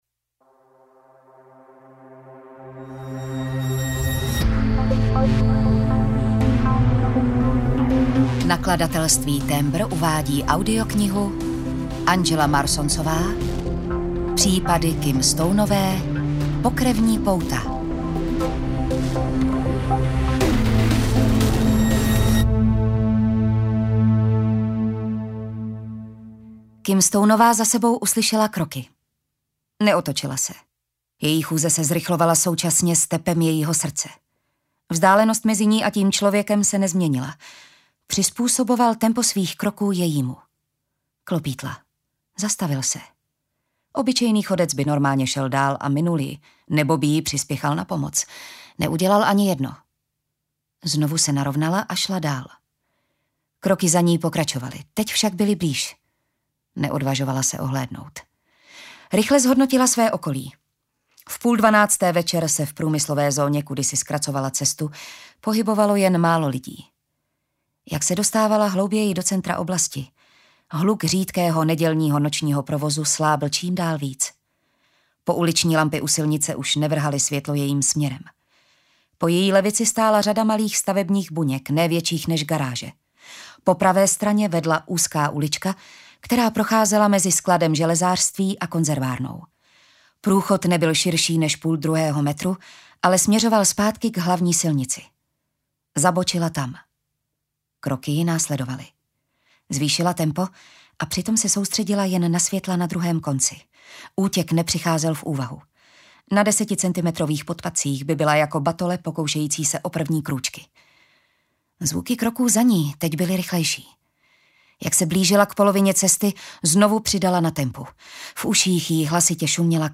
Pokrevní pouta audiokniha
Ukázka z knihy